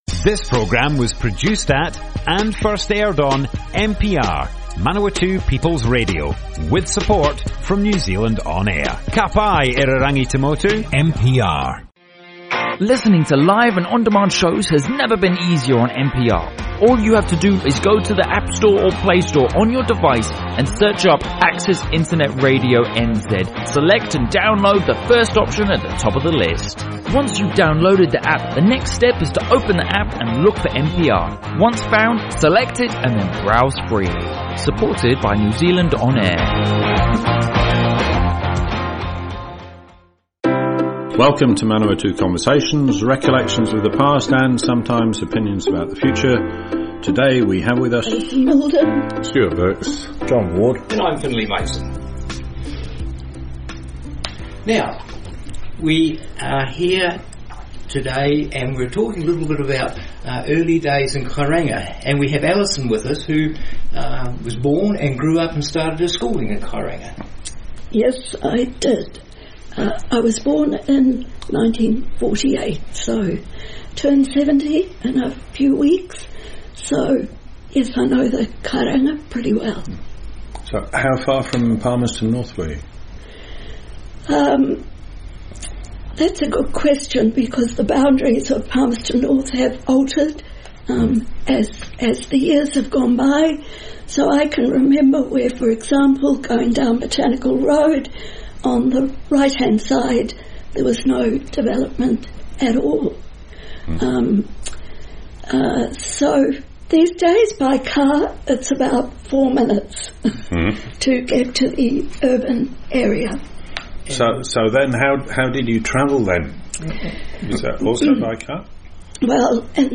Manawatu Conversations More Info → Description Broadcast on Manawatu People's Radio 4 December 2018.
oral history